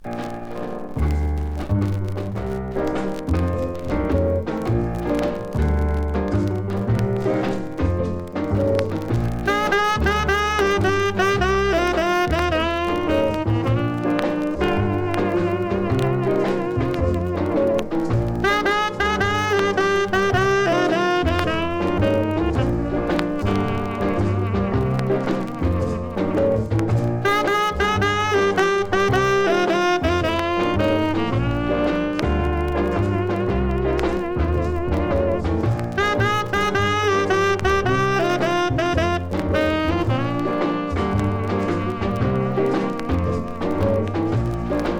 Jazz, Rhythm & Blues　USA　12inchレコード　33rpm　Mono